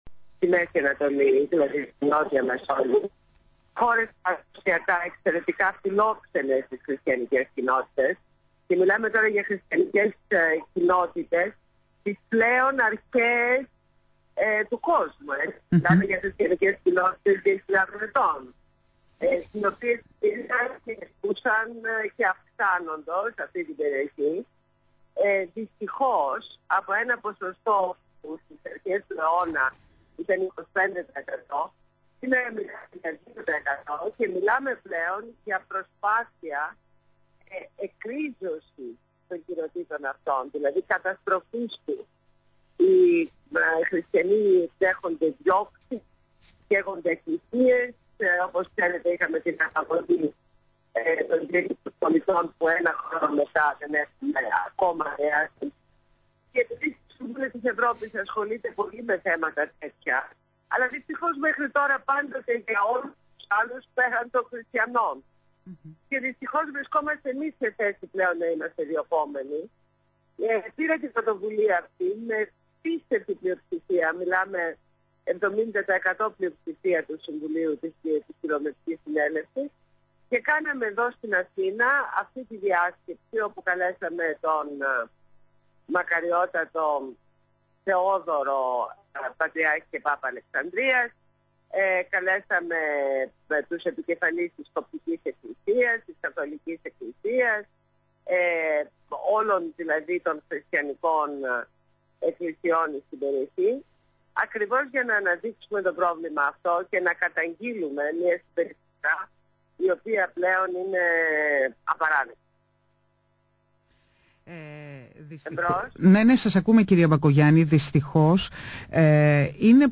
Συνέντευξη στο ραδιόφωνο Εκκλησίας της Ελλάδος